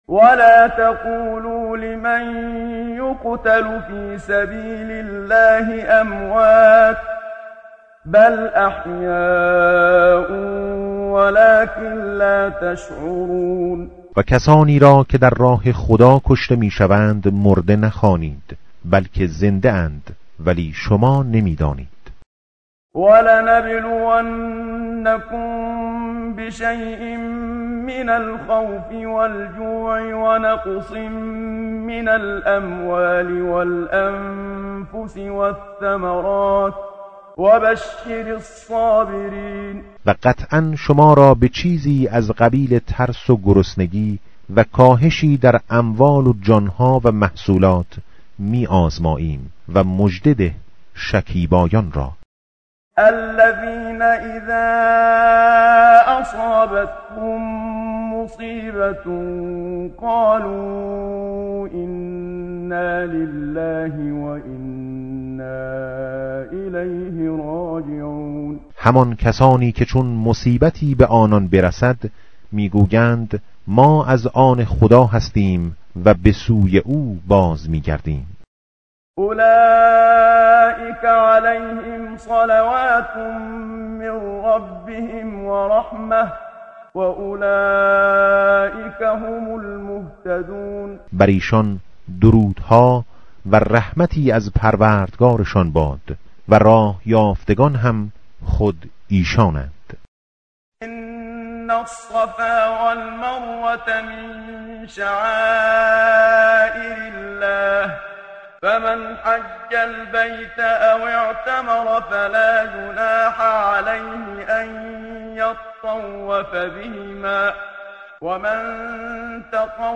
tartil_menshavi va tarjome_Page_024.mp3